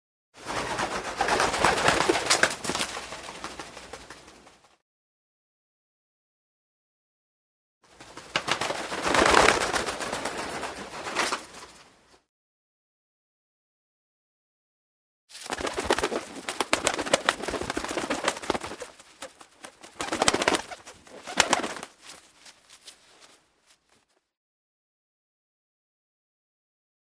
Tiếng chim Bồ Câu Vỗ Cánh
Nó không chỉ đơn thuần là tiếng động cơ học, mà là sự kết hợp giữa nhịp đập dồn dập của đôi cánh vào không khí và tiếng "phạch phạch" nhẹ nhàng. Bản thu này ghi lại khoảnh khắc một hoặc nhiều chú chim bồ câu bất ngờ cất cánh, tạo nên cảm giác về sự chuyển động nhanh, đột ngột nhưng vẫn mang đậm hơi thở của hòa bình và tự do.
• Nhịp điệu dồn dập: Âm thanh bắt đầu với cường độ mạnh và nhỏ dần khi chim bay xa, tạo hiệu ứng không gian 3D rất tốt.
• Tính chân thực cao: Bản thu bao gồm cả tiếng gió lướt qua cánh (Whoosh) và tiếng xào xạc của lông vũ, giúp người nghe có cảm giác chim đang bay ngay sát bên tai.
• Chất lượng bản thu chuyên nghiệp: File đã được khử nhiễu môi trường, tập trung hoàn toàn vào tần số âm thanh của đôi cánh, giúp bạn dễ dàng lồng ghép vào bất kỳ nền nhạc nào.